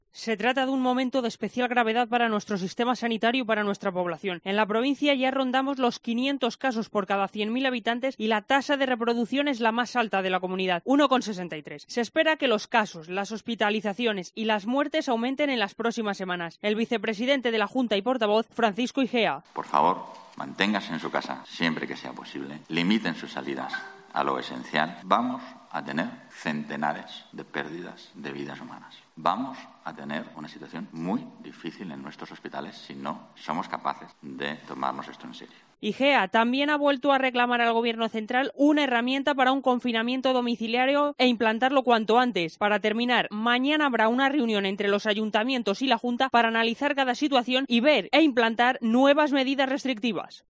Este es el mensaje con el que ha empezado el portavoz de la Junta la rueda de prensa posterior al Consejo de Gobierno de este jueves donde ha evidenciado con datos y gráficos una "pared" en el crecimiento de casos de coronavirus en las dos últimas semanas para pronosticar que se van a necesitar todas las camas de intensivos y que en dos semanas habrá una situación "muy difícil".